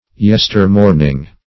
Search Result for " yester-morning" : The Collaborative International Dictionary of English v.0.48: Yestermorn \Yes"ter*morn`\, Yester-morning \Yes"ter-morn`ing\, n. The morning of yesterday.